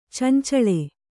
♪ cancaḷe